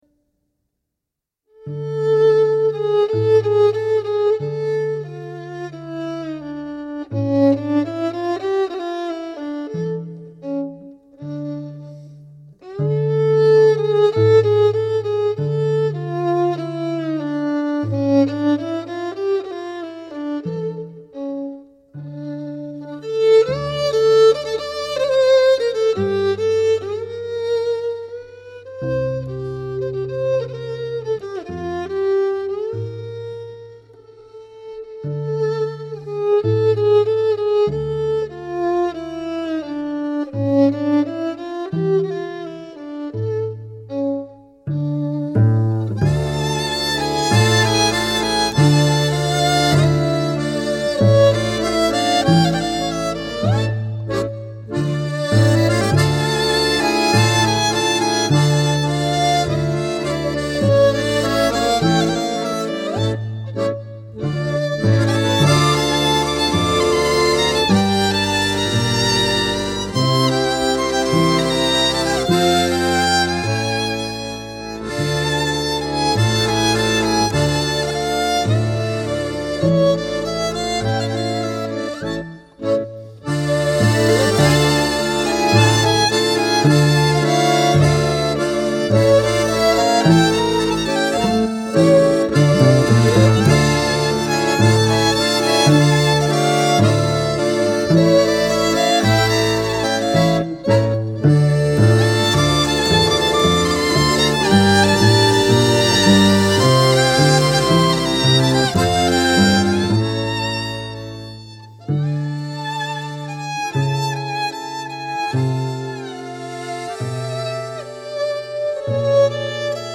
En este caso, una canción yiddish, nos sirve como telón de fondo: